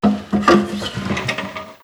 Abrir la tapa de un baúl
Sonidos: Acciones humanas
Sonidos: Hogar